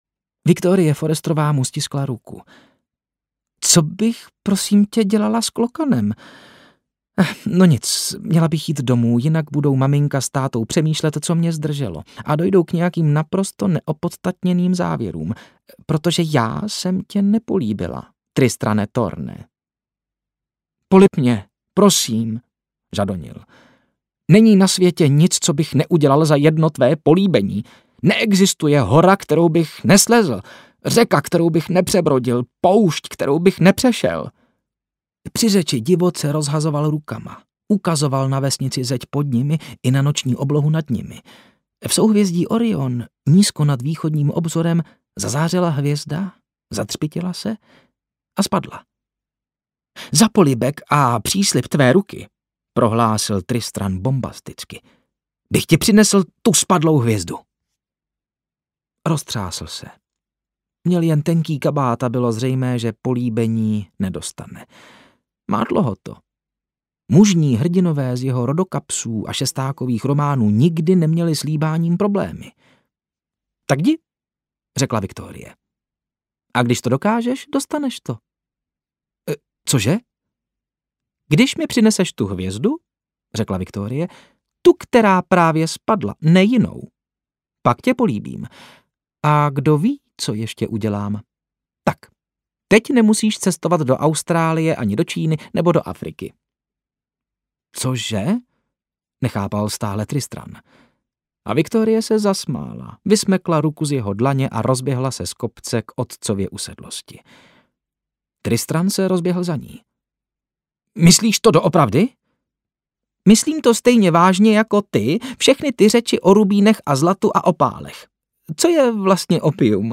Hvězdný prach audiokniha
Ukázka z knihy